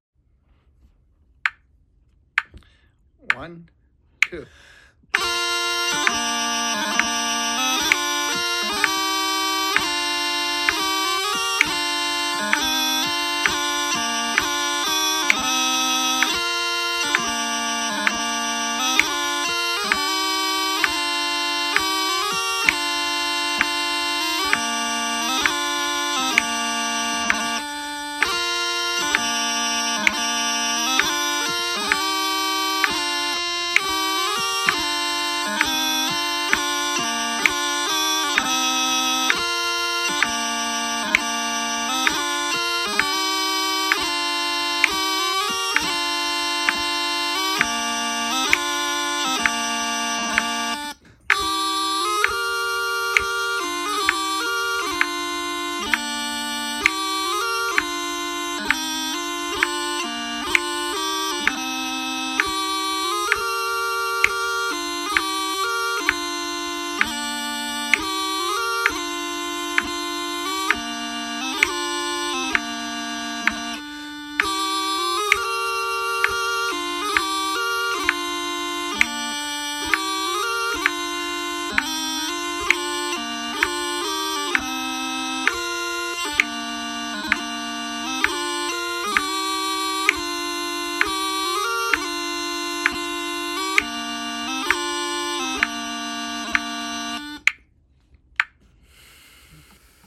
bloodyfiedlsofflanders1st2ndpartwithharmonies65pbmv1.0.mp3